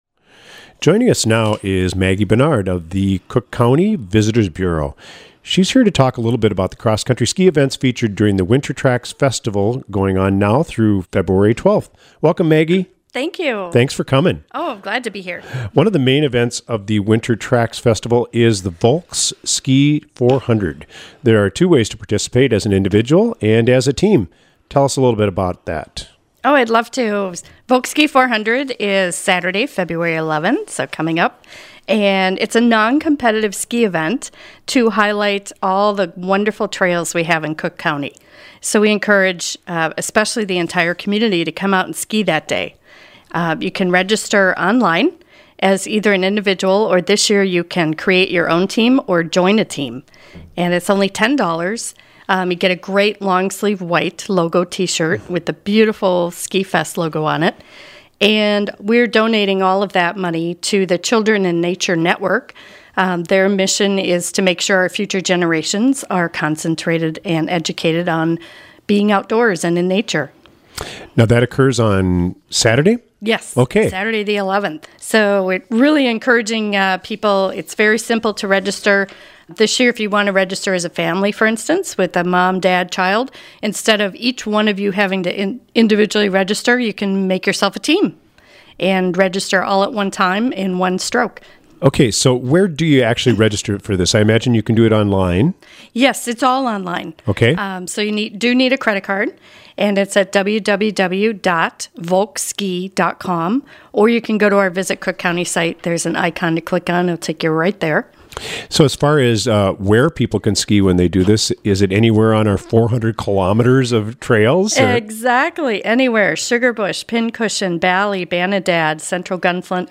Attached is an interview